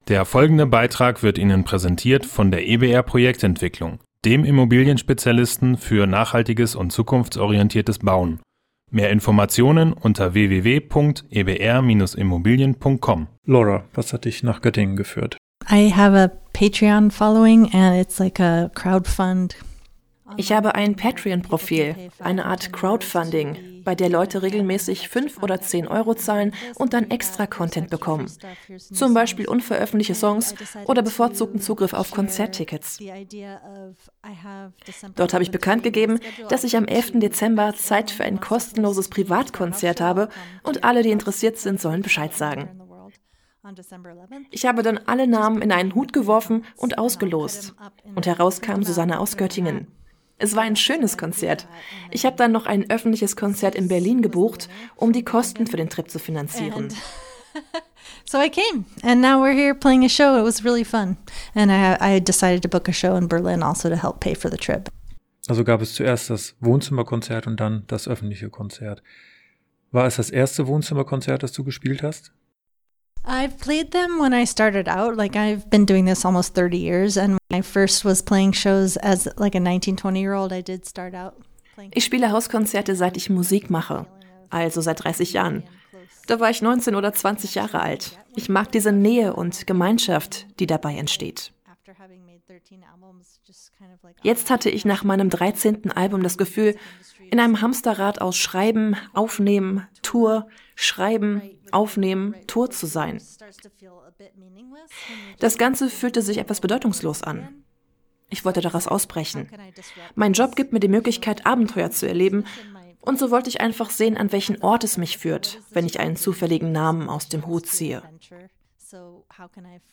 Beiträge > Wohnzimmerkonzert und deutsche Flitter-Flatter Fenstergehänge: Interview mit Sängerin Laura Veirs - StadtRadio Göttingen
interview_laura_veirs_stadtradio_goettingen-playout.mp3